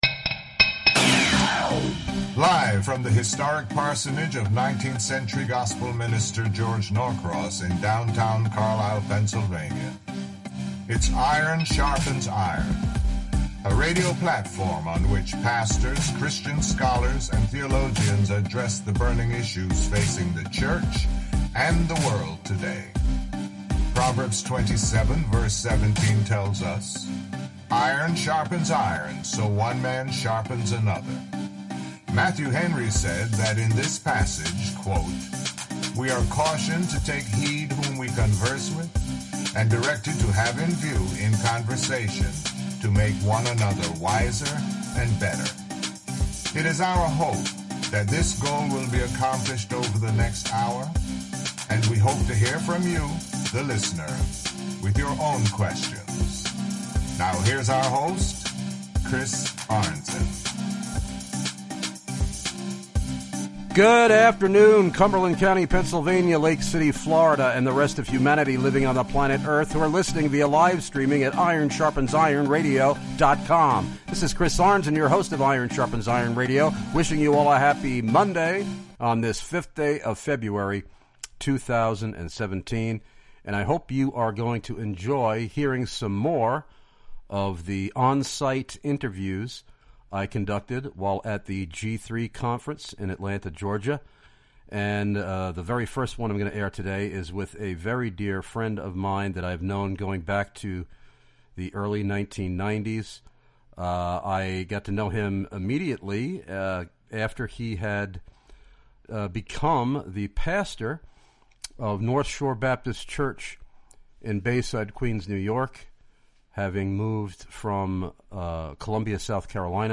Interviews recorded LIVE at the 2018 G3 CONFERENCE!!!
These interviews were conducted on-site from the Iron Sharpens Iron Radio booth in the exhibition hall of the Georgia International Convention Center in Atlanta.